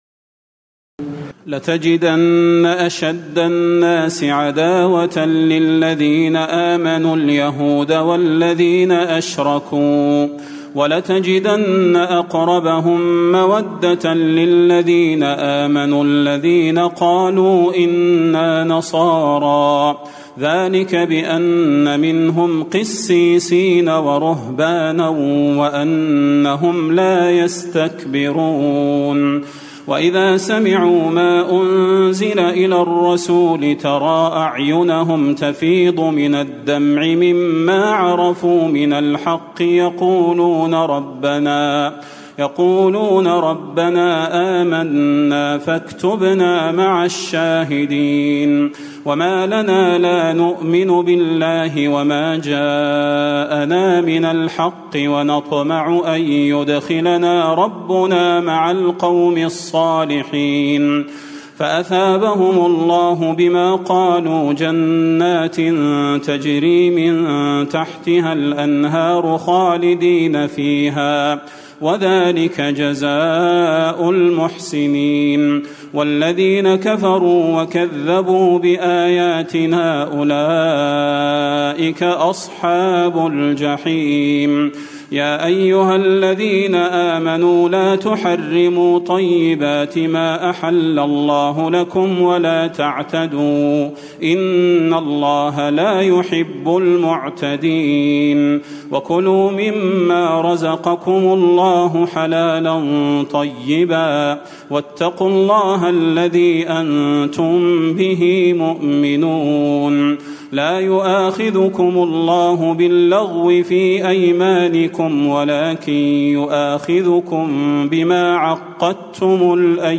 تراويح الليلة السابعة رمضان 1436هـ من سورتي المائدة (82-120) و الأنعام (1-35) Taraweeh 7 st night Ramadan 1436H from Surah AlMa'idah and Al-An’aam > تراويح الحرم النبوي عام 1436 🕌 > التراويح - تلاوات الحرمين